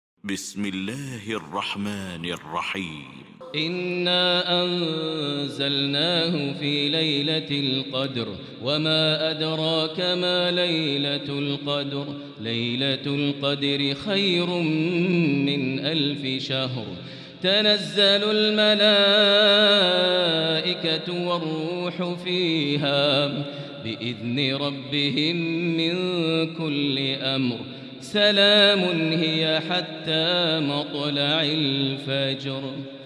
المكان: المسجد الحرام الشيخ: فضيلة الشيخ ماهر المعيقلي فضيلة الشيخ ماهر المعيقلي القدر